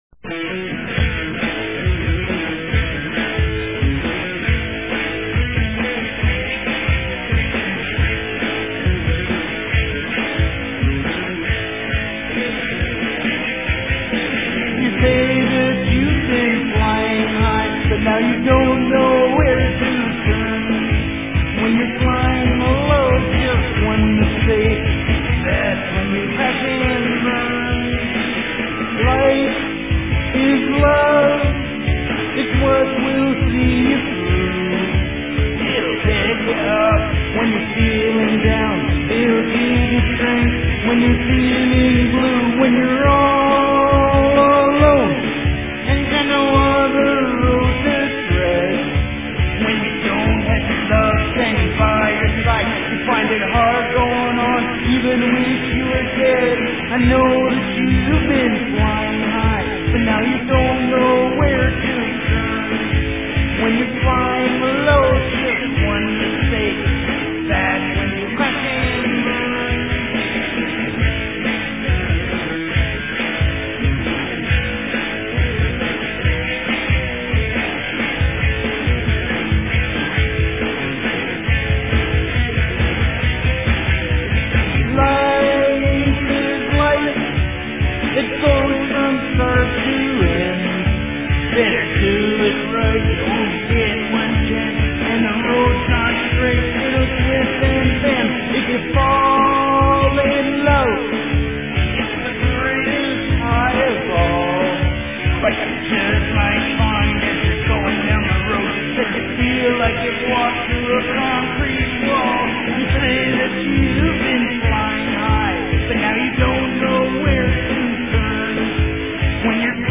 CLICK to listen to a song-writing demo: